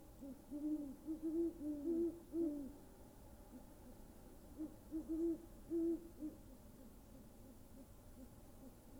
Great Horned Owls that I recorded in our backyard.
Graph of Amplitude over Time of Great Horned Owls